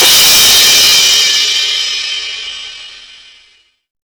CRASHDIST1-L.wav